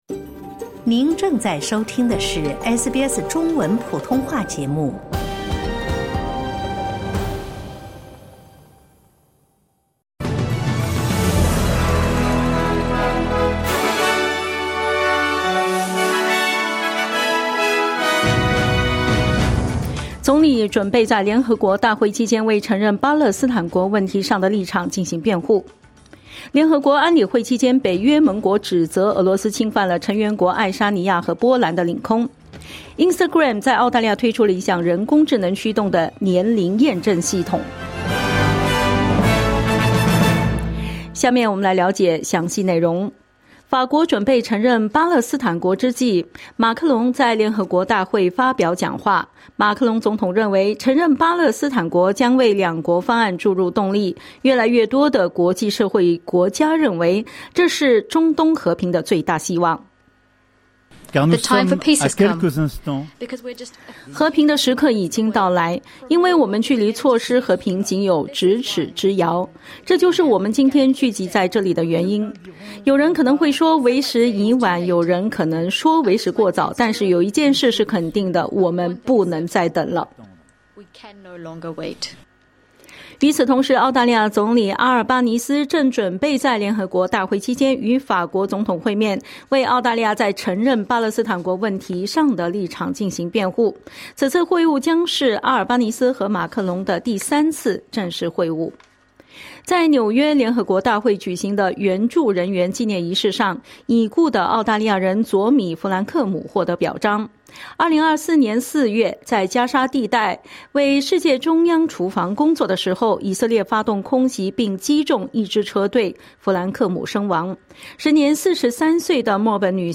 SBS早新闻（2025年9月23日）